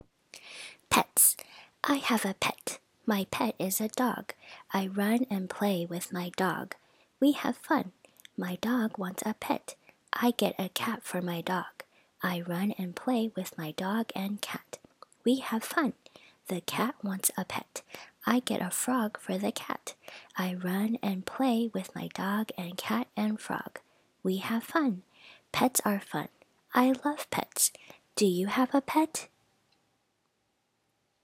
■普通の速さ